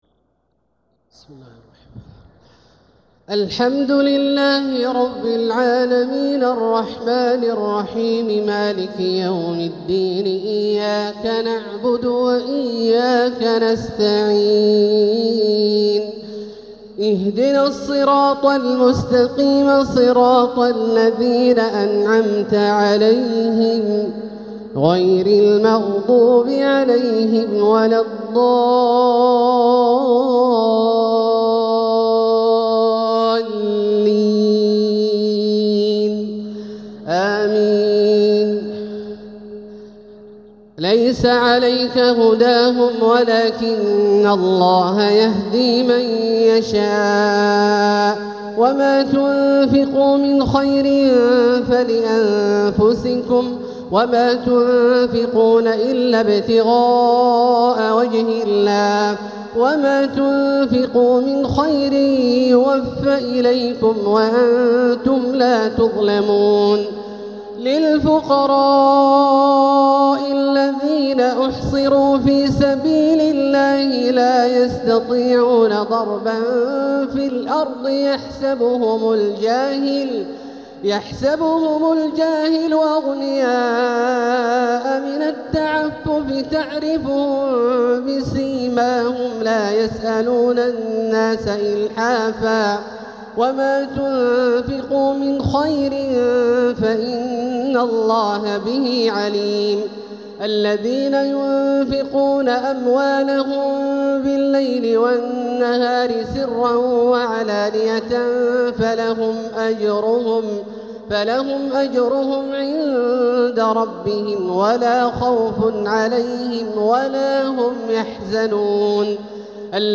تراويح ليلة 4 رمضان 1446هـ من سورتي البقرة {272 -286} و آل عمران {1-25} Taraweeh 4th night Ramadan 1446H Surah Al-Baqara Surah Aal-i-Imraan > تراويح الحرم المكي عام 1446 🕋 > التراويح - تلاوات الحرمين